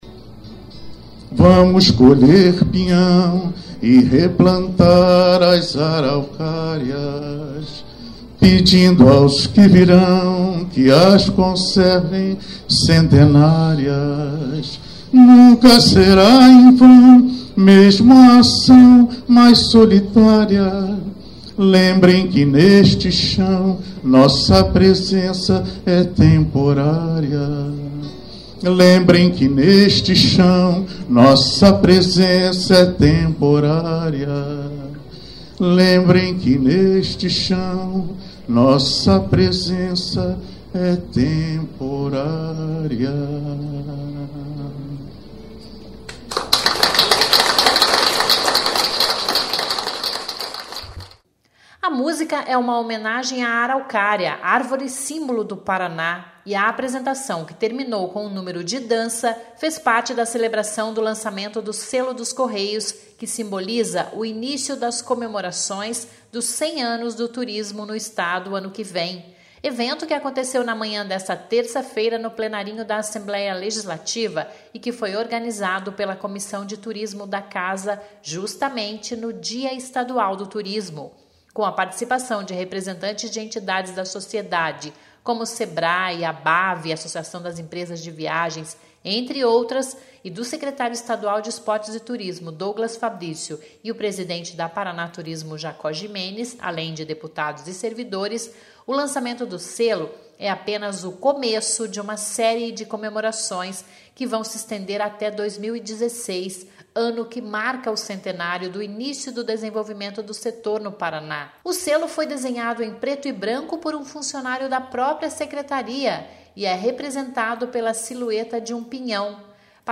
Começa com um sobe som...